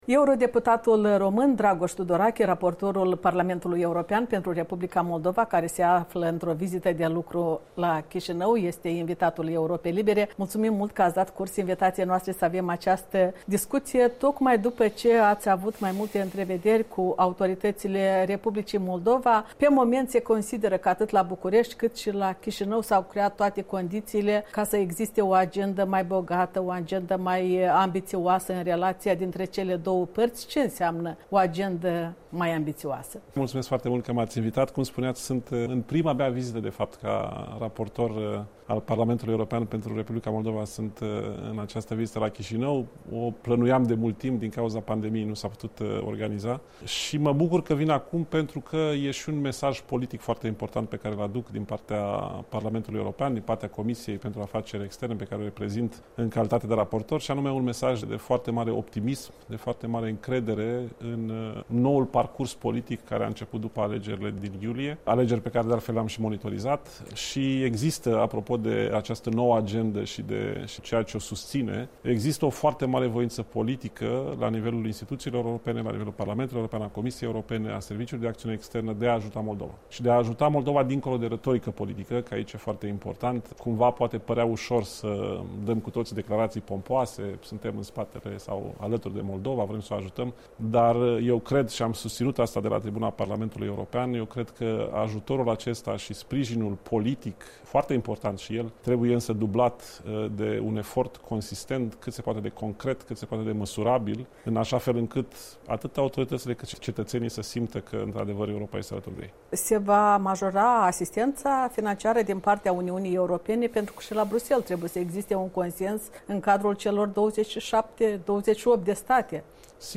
Interviu cu europarlamentarul român Dragoș Tudorache